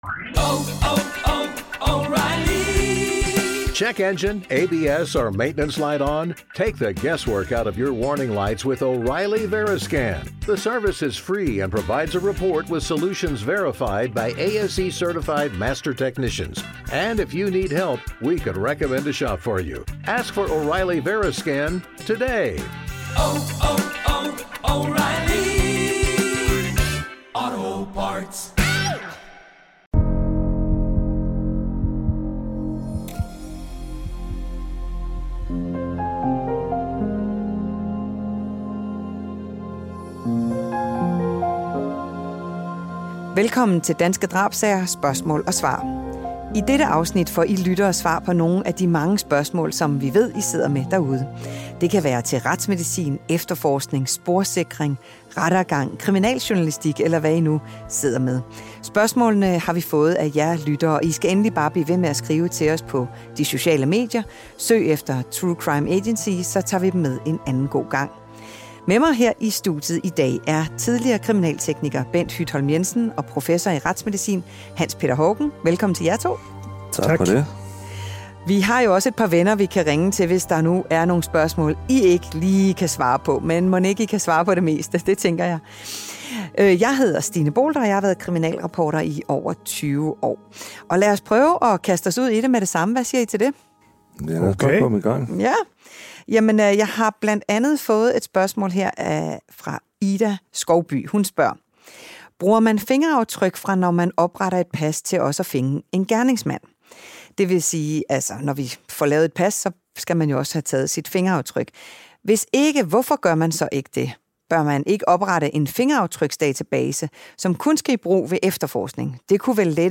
I dette særafsnit får I lyttere svar på nogle af de spørgsmål, I har sendt ind til os. Det kan både være indenfor retsmedicin, efterforskning, sporsikring, rettergang og kriminaljournalistik.